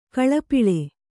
♪ kaḷapiḷe